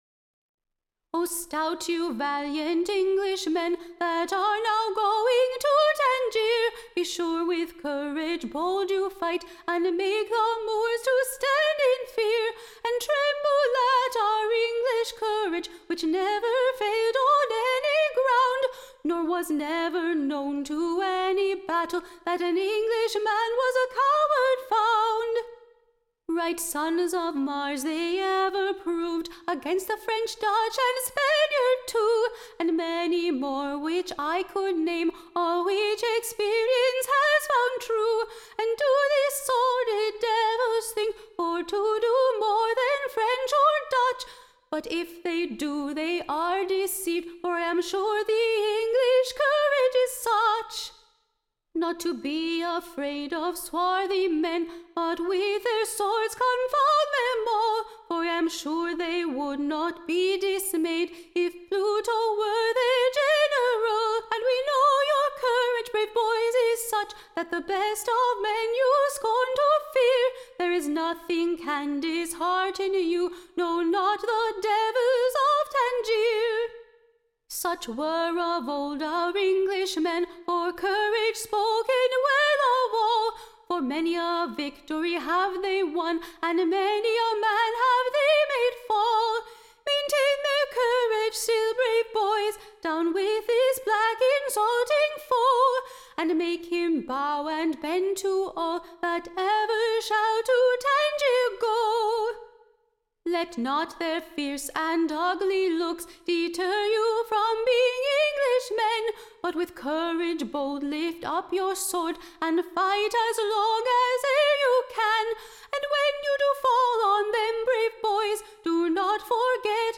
Recording Information Ballad Title The ENGLISH Courage / UNDAUNTED: / OR, / Advice to those Brave Valiant Blades now going to / TANGIER, / To Maintain the Old English Courage against the / MOORS.
Tune Imprint To a pleasant New Tune.